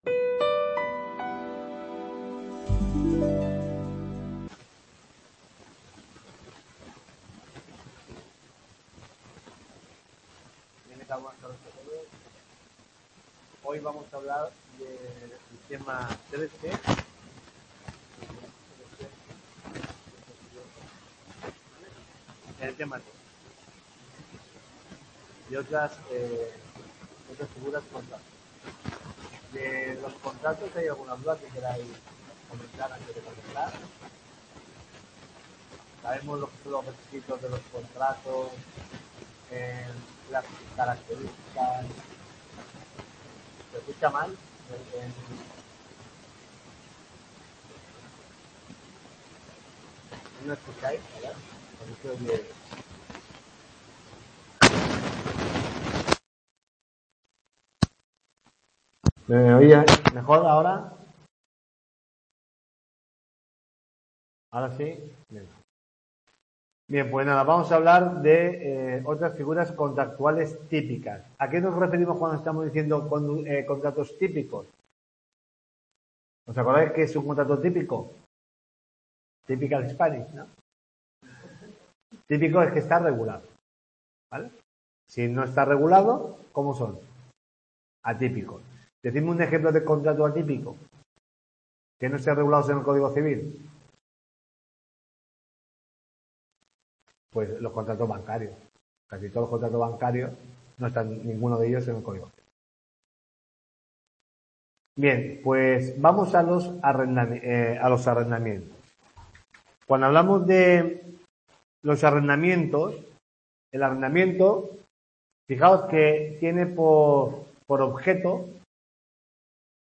TUTORIA 8